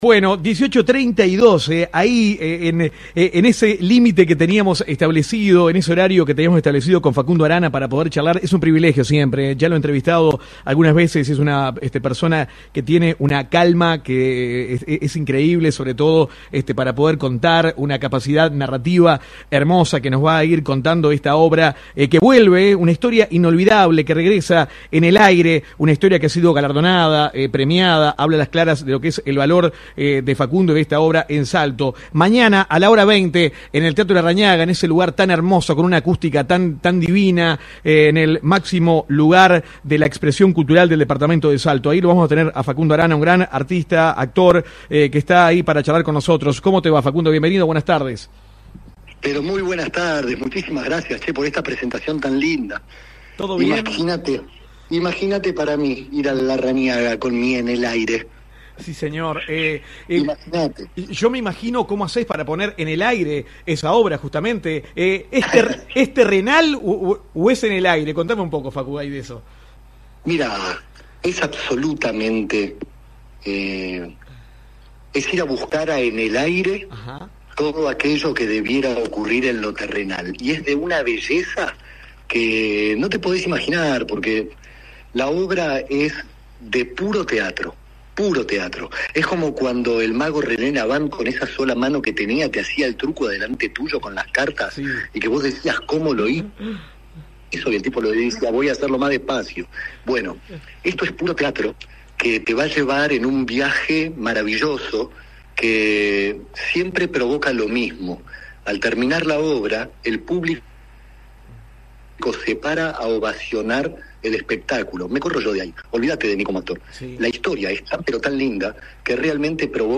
Comunicación telefónica con Facundo Arana